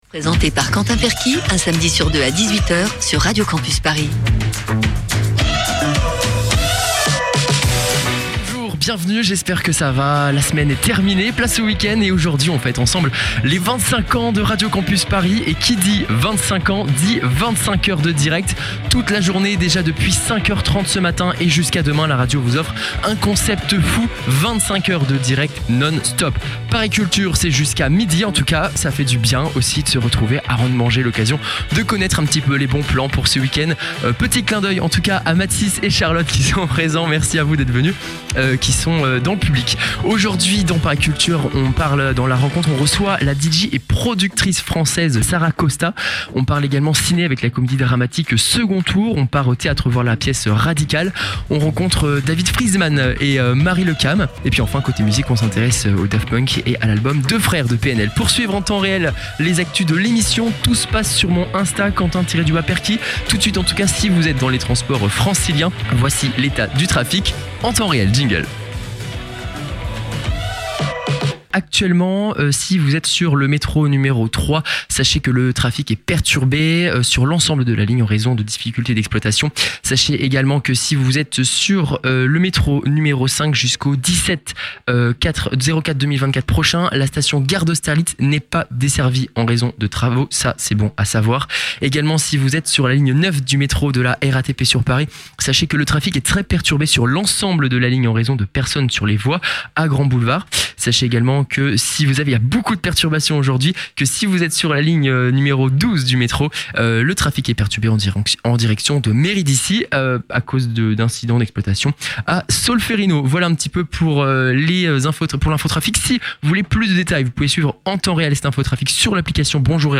On fête aujourd’hui les 25 ans de Radio Campus Paris et Paris Culture s’insère au cœur de 25H de direct !